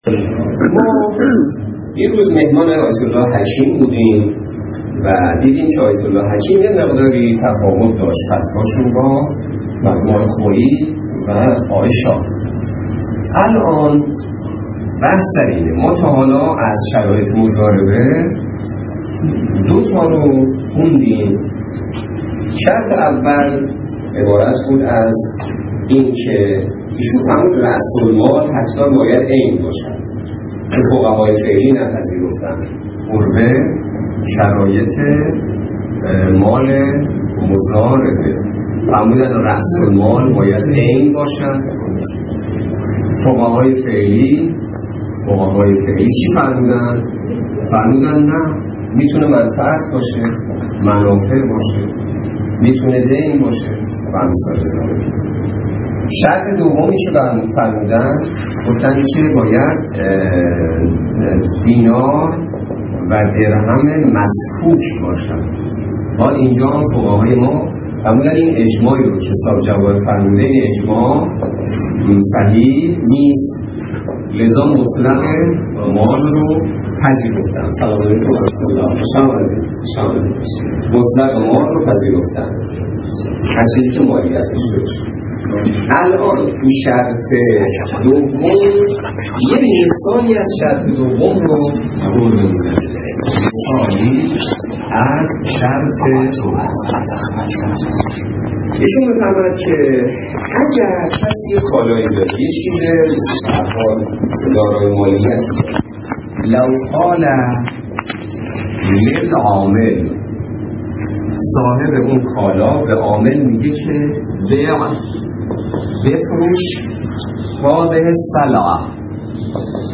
درس فقه